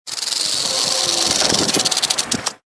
rattlesnake_sound